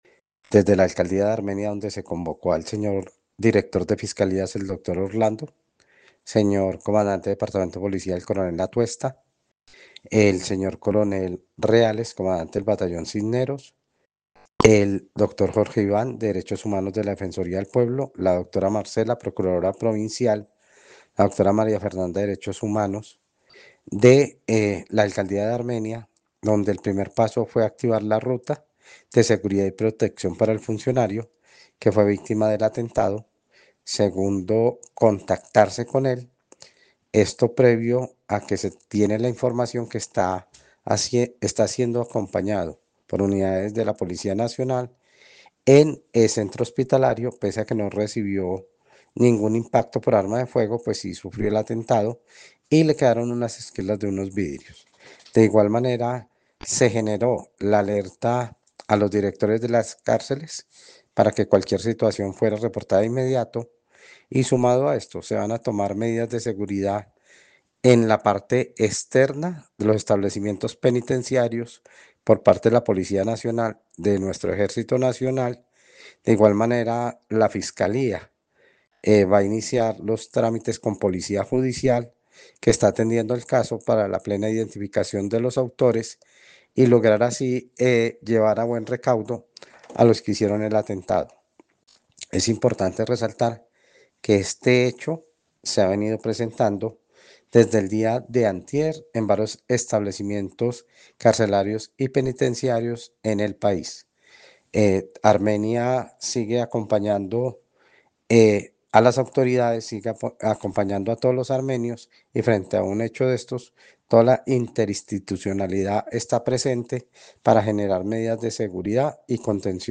Secretario de Gobierno de Armenia sobre atentado